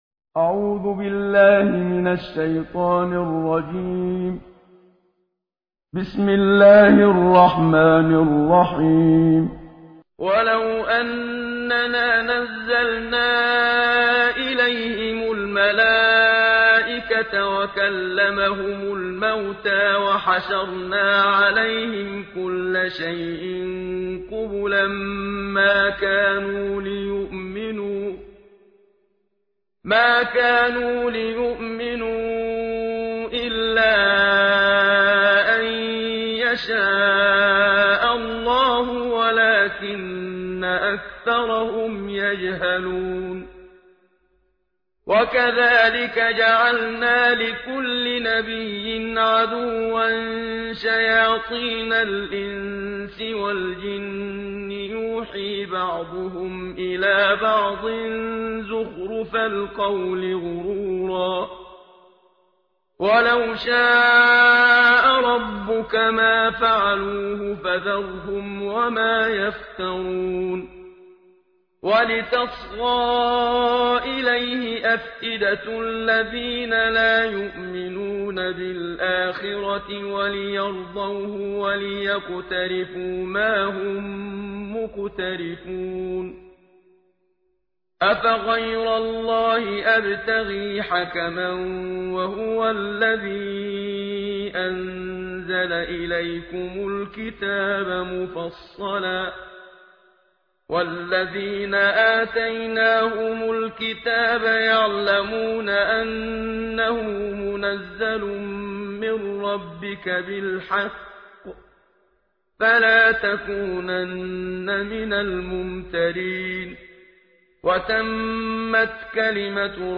جزء ۸ قرآن مجید با صدای استاد محمد صدیق منشاوی/متن+ترجمه